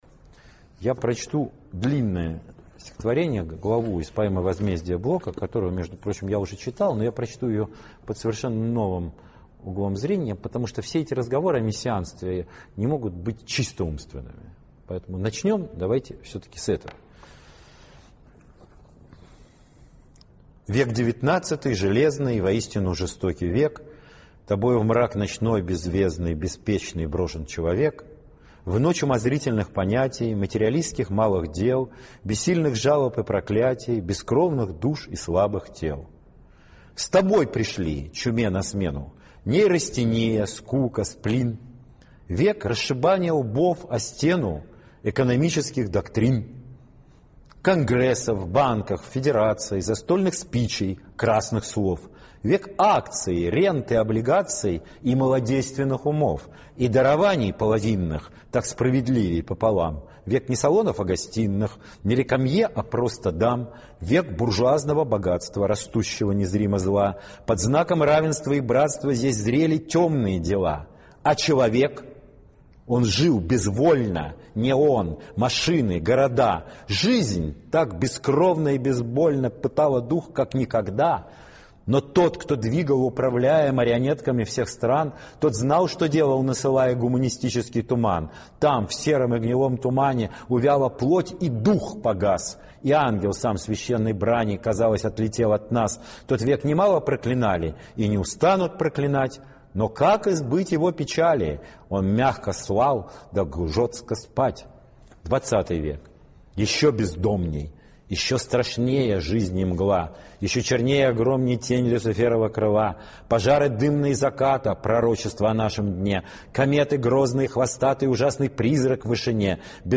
2. «+ШС 01 – “Возмездие”, Блок А. А. (читает Кургинян С. Е.)» /